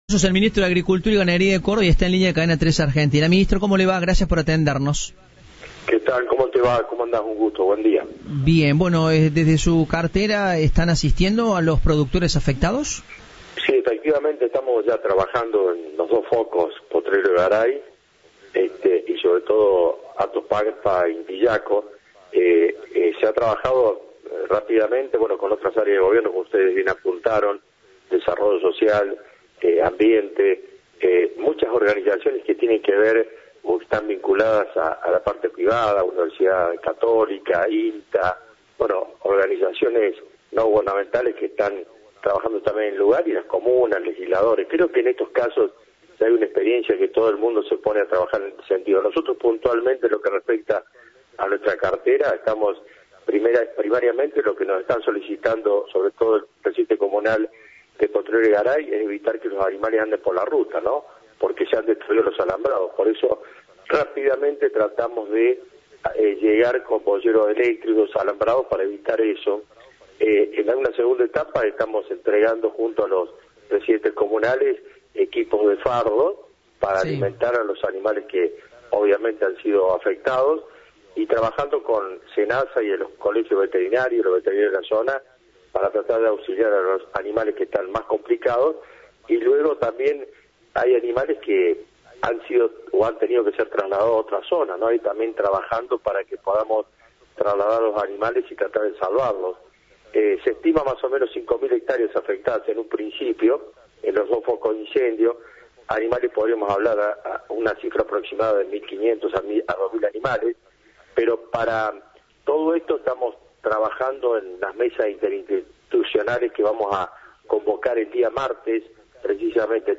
En diálogo con Cadena 3, el ministro de Agricultura y Ganadería de Córdoba dijo que están trabajando para socorrer a las especies afectadas por el fuego en Potrero de Garay, Atos Pampa e Intiyaco.